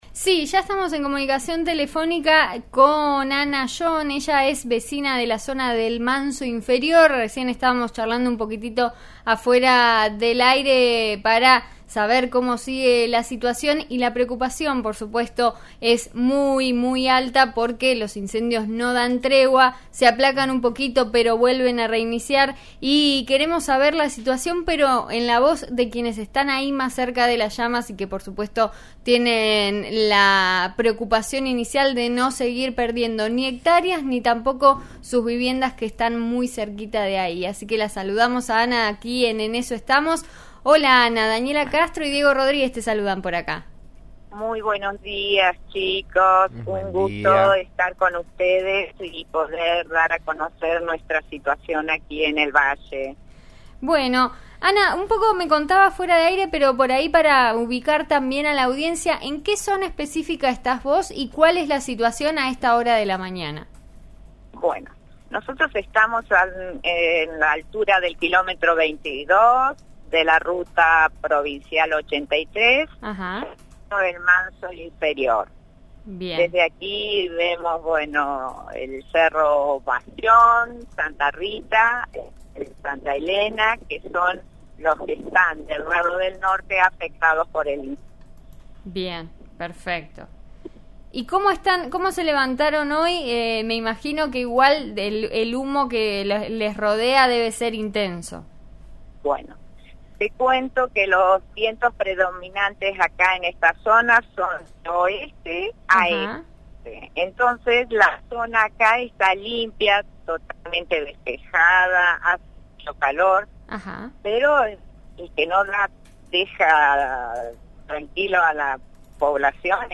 contó al aire de 'En Eso Estamos' de RN RADIO cómo conviven con la cercanía de las llamas.